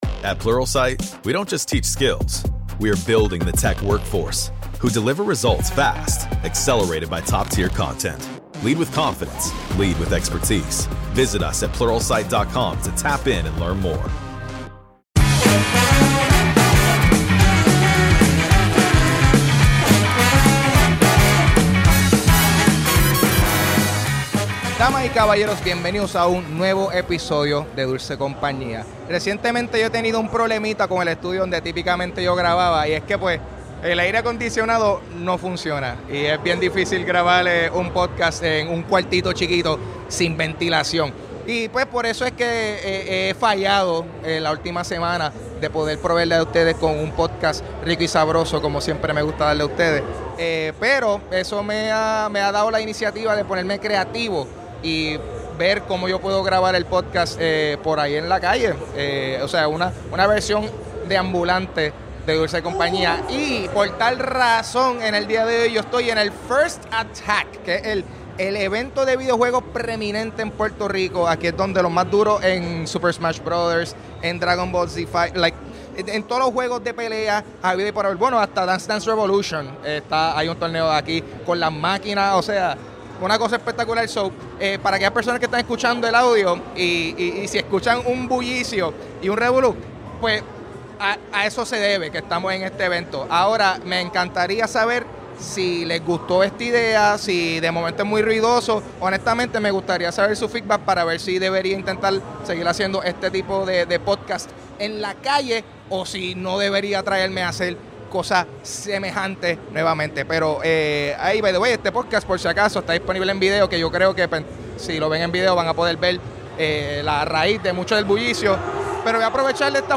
Fuí al First Attack, el torneo de videojuegos más importante de Puerto Rico, y hablé con algunos compañeros presentes sobre jugar competitivamente, ser el "hype man" de los torneos y hacer cosplay.